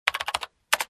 Keyboard1.wav